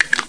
00075_Sound_Cliclac.mp3